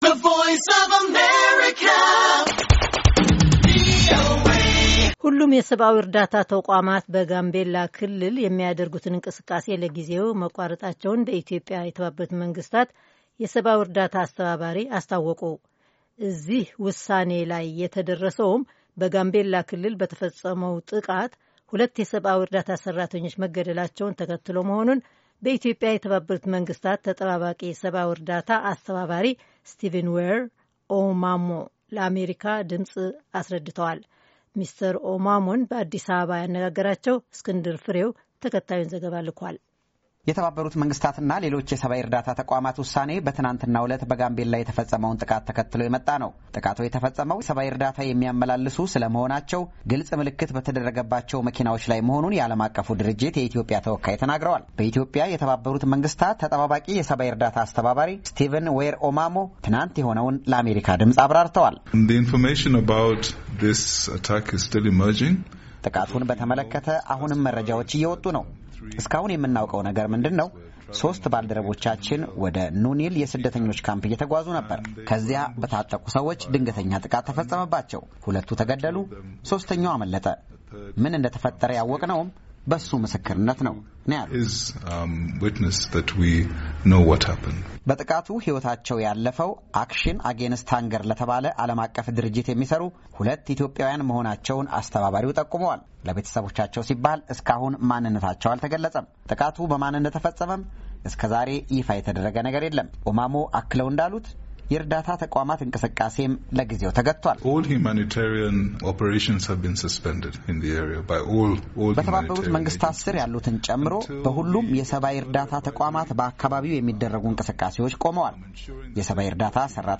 ሚስተር ኦማሞን አነጋግረናቸዋል፡፡